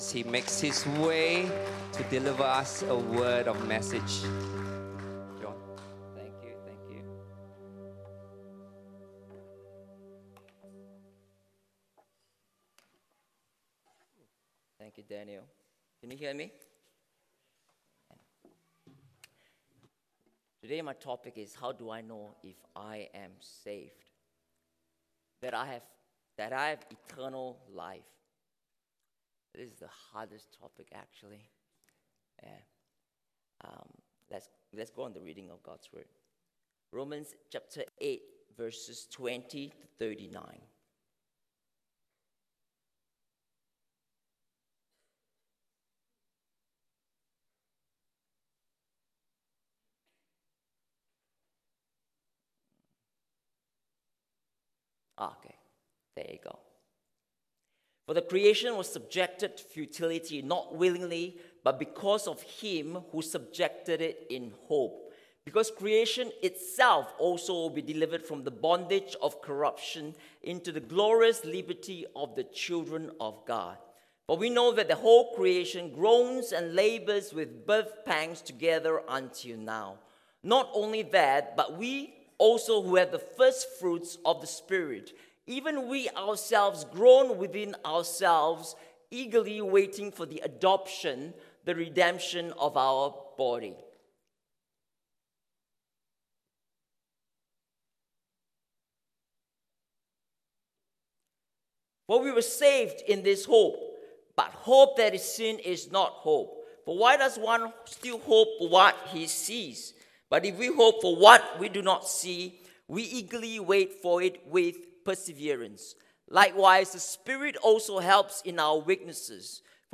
English Worship Service 22 Oct 2023
Sermon Notes